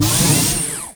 Door.wav